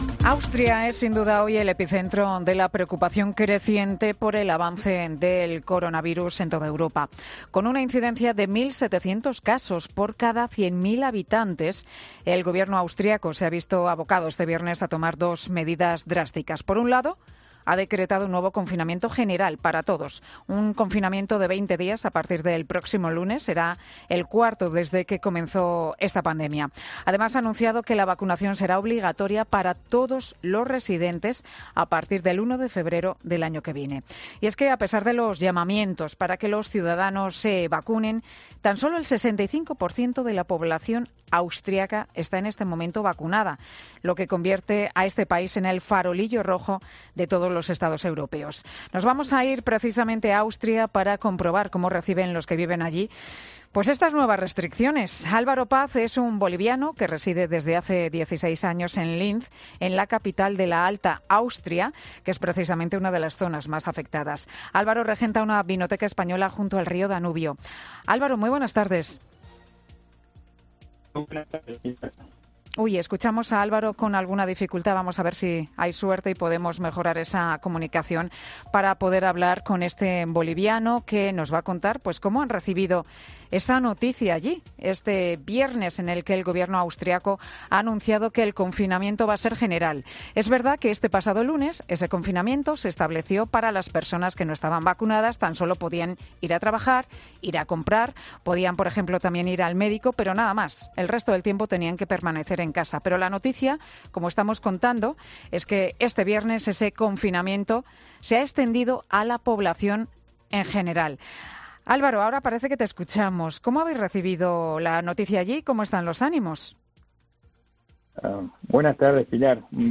Un boliviano en Austria, sobre el confinamiento total: La situación es triste, incómoda e incierta